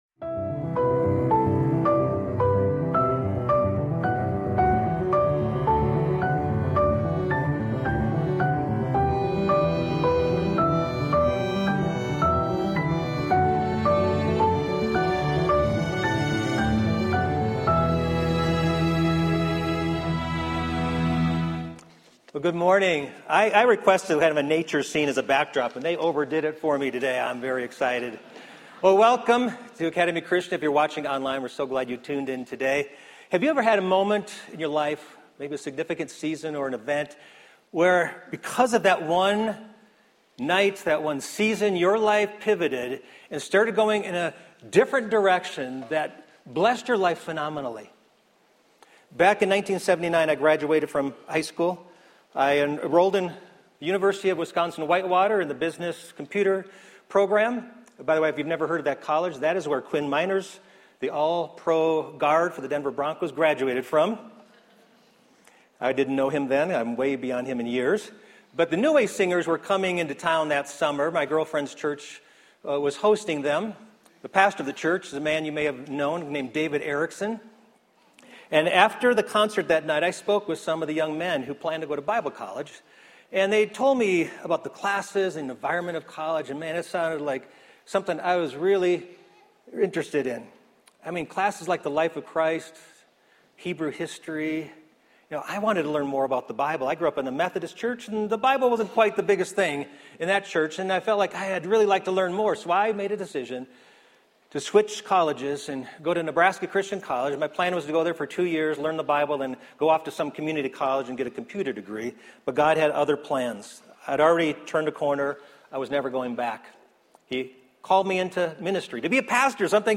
A message from the series "Majoring on the Minors."